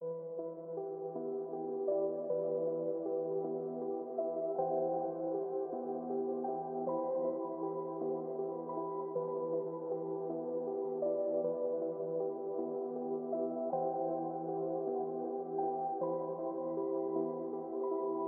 标签： 105 bpm Trap Loops Piano Loops 3.08 MB wav Key : F
声道立体声